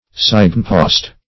Signpost \Sign"post`\, n.